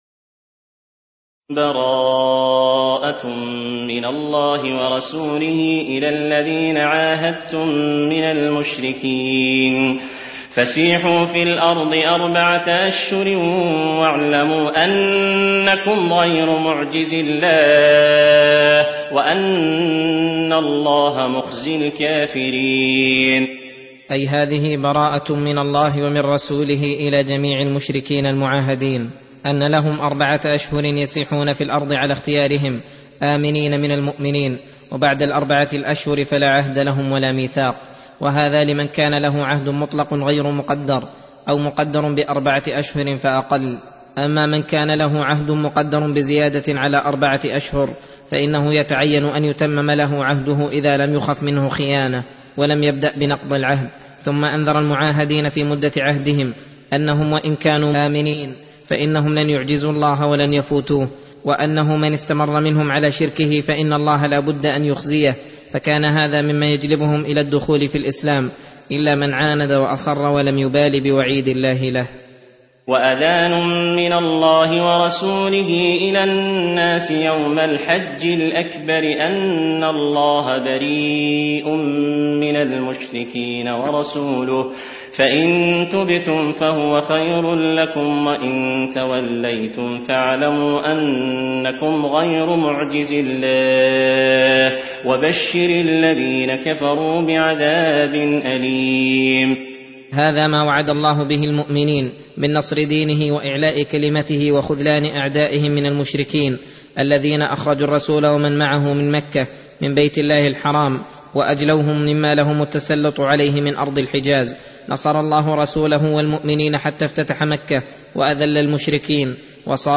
درس (16) : تفسير سورة التوبة (1-15)